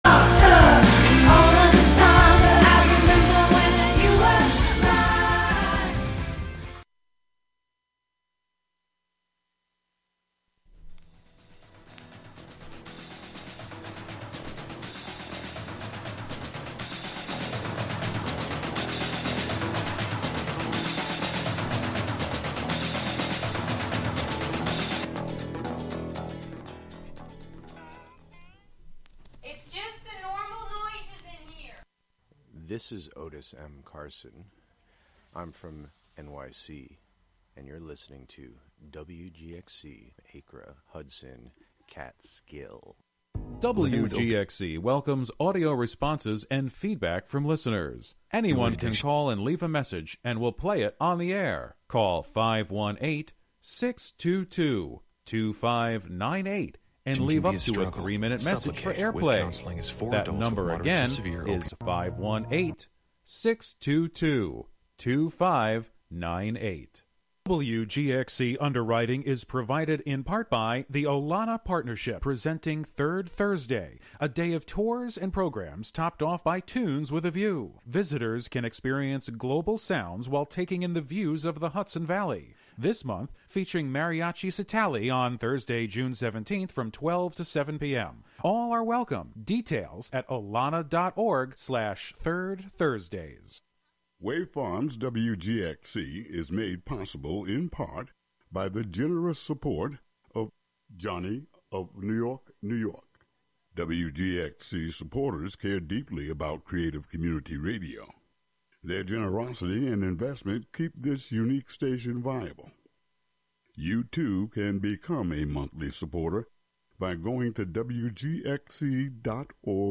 Expect immersive conversation, timeless tracks, and a playlist designed to stir memory, motivation, and momentum.